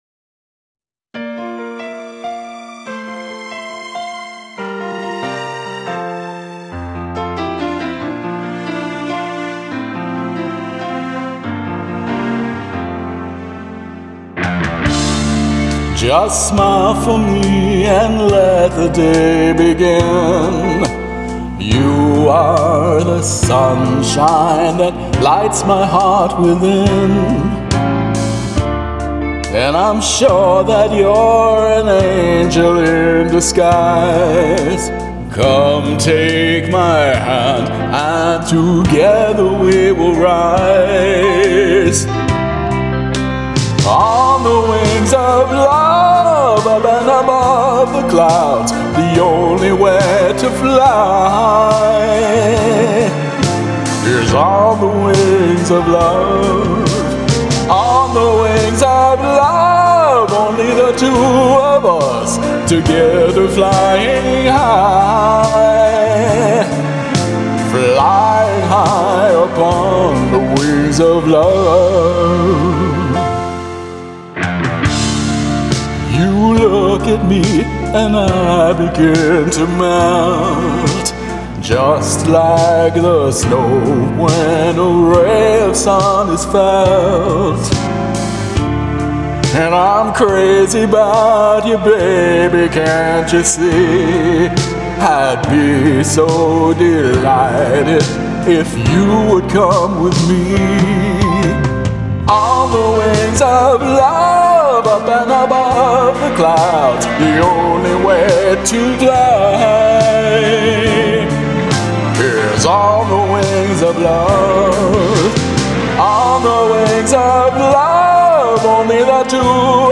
Lower key.
Shorter ending.